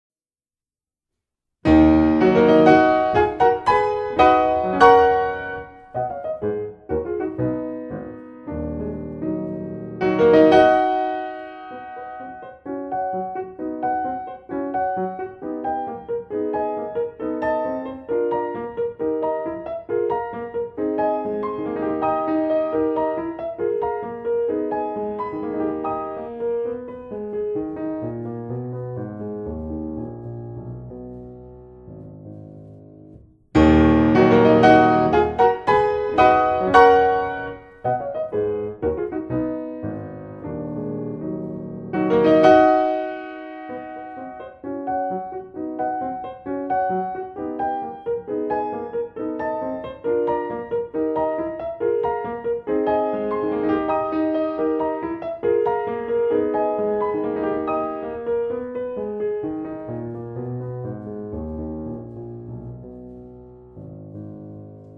zur vorgegebenen Liedbegleitung zu singen